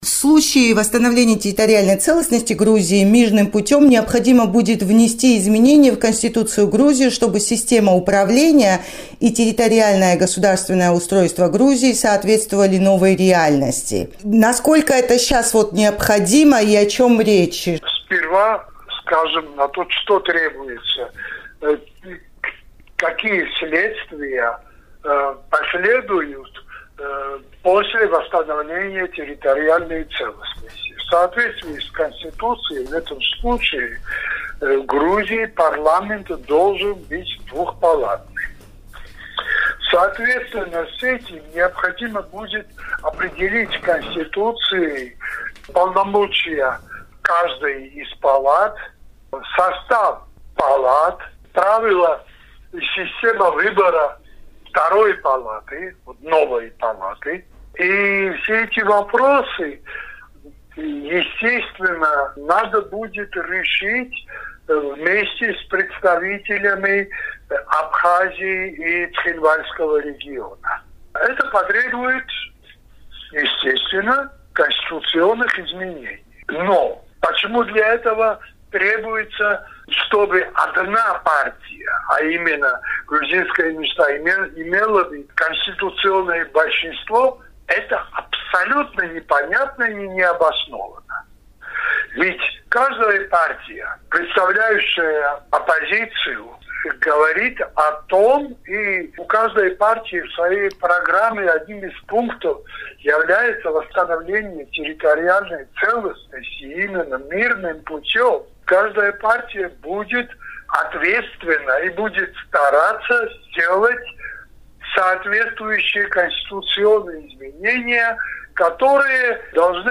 Интервью с одним из авторов действующей Конституции Грузии